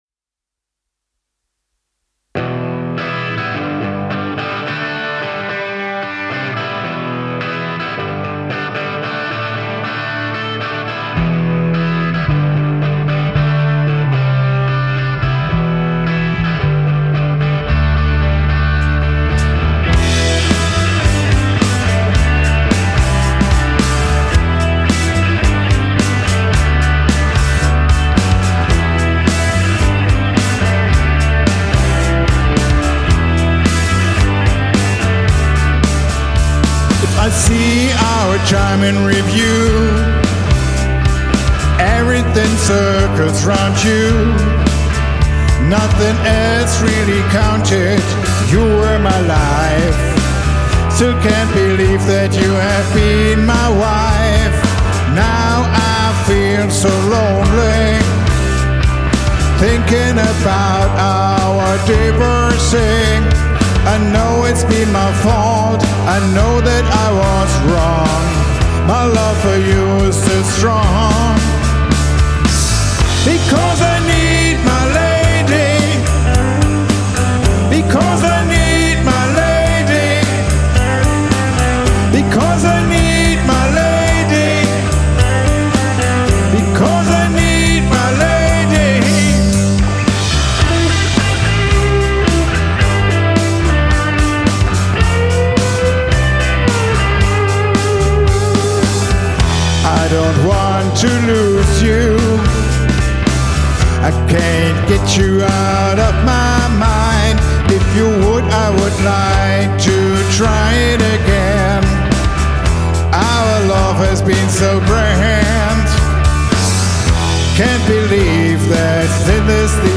Gesang und Gitarre
Backing Vocals und Leadgitarre
Bass
Schlagzeug